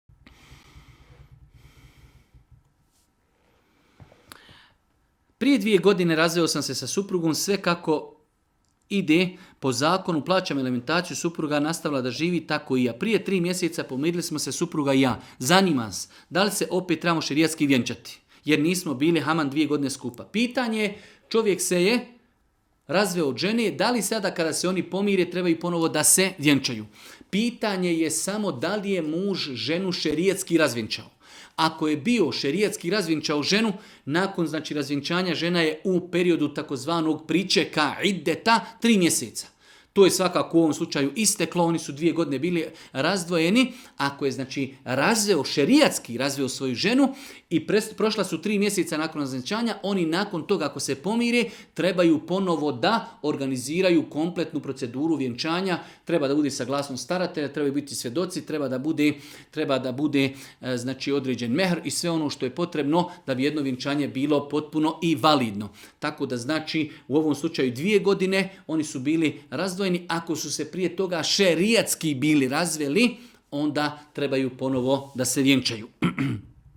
Audio isječak odgovora Tvoj web preglednik ne podrzava ovaj fajl, koristi google chrome.